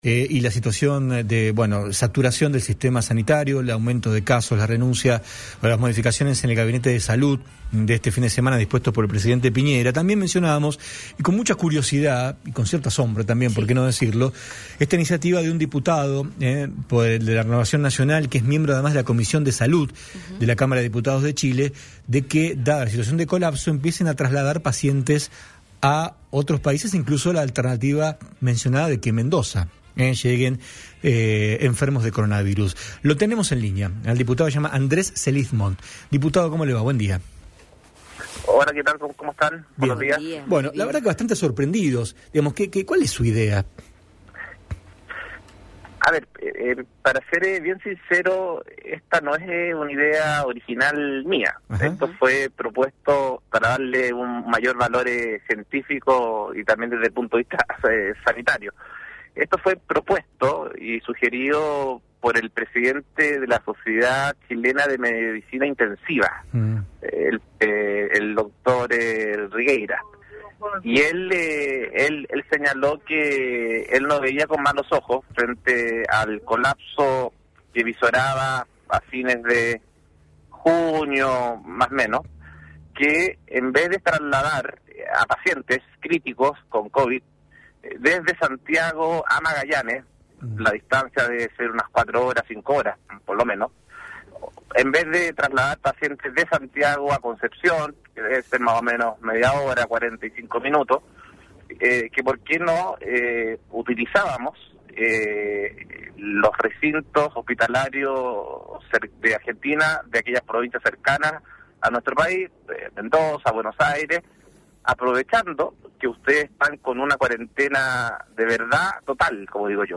Escuchá la entrevista con el programa «Uno nunca sabe» de MDZ Radio.